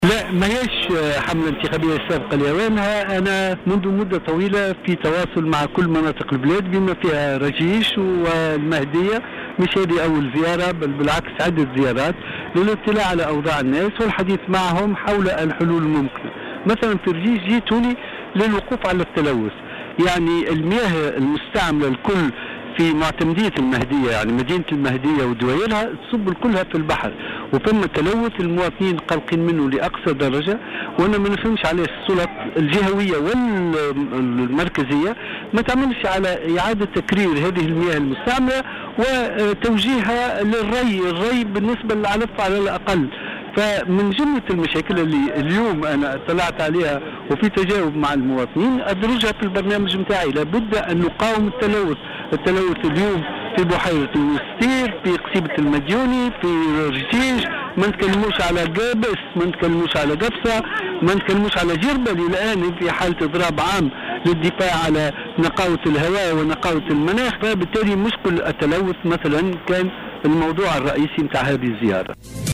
قال مرشّح الحزب الجمهوري للانتخابات الرئاسية،أحمد نجيب الشابي في تصريح اليوم الجمعة ل"جوهرة أف أم" إنه من المهم تحقيق شروط الشفافية حتى لا يقع الطعن في نتائج الانتخابات القادمة.